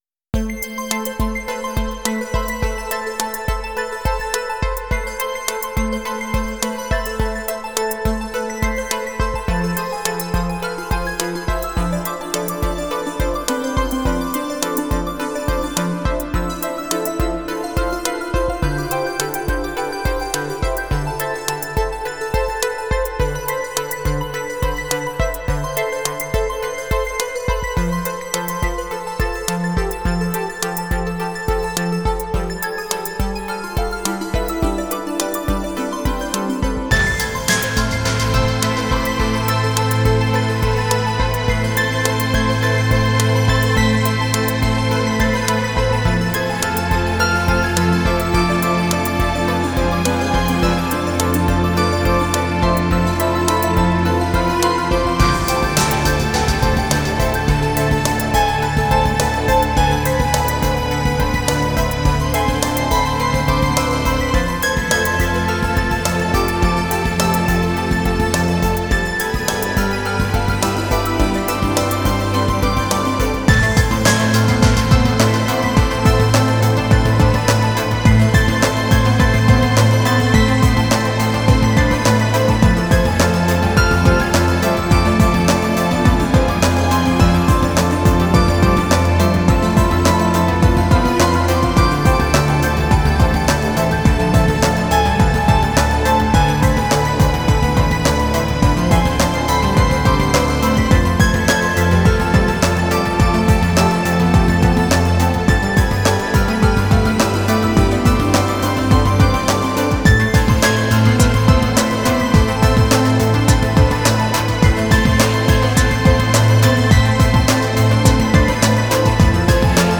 Genre: Enigmatic.